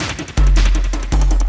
53 LOOP 02-L.wav